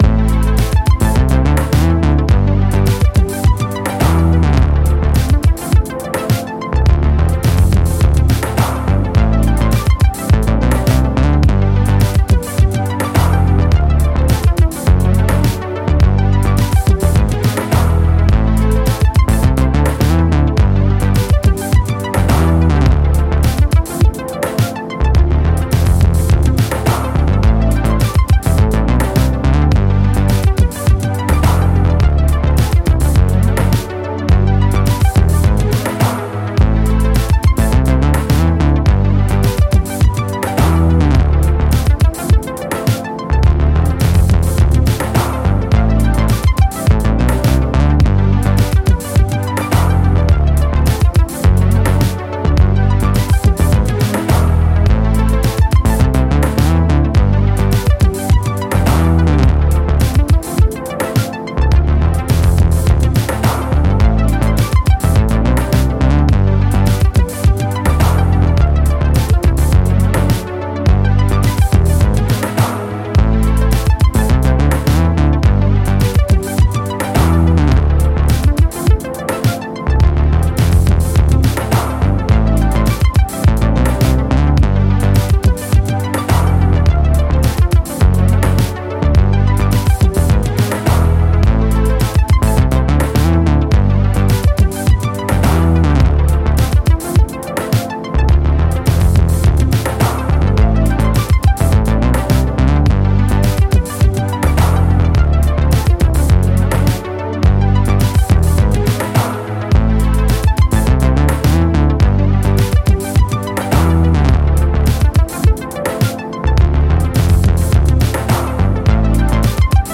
标签： 舞曲 电子音乐 节拍 循环 处理
声道立体声